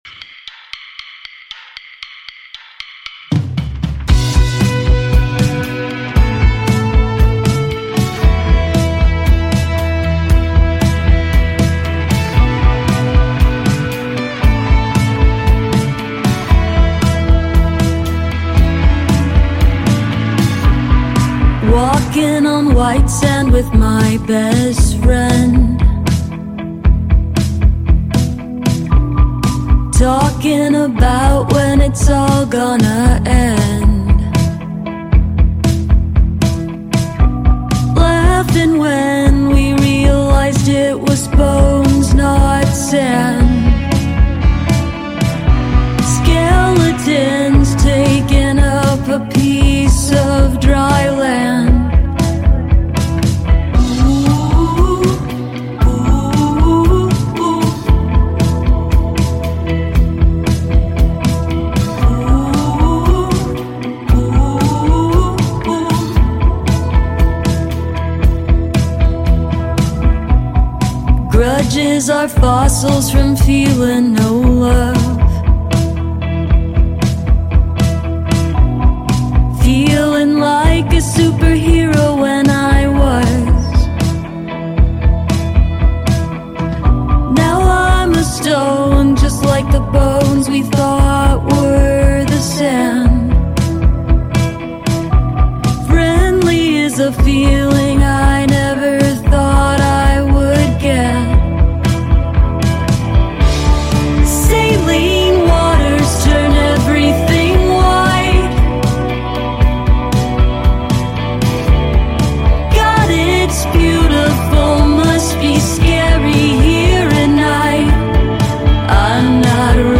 Closed on Sundays set and interview.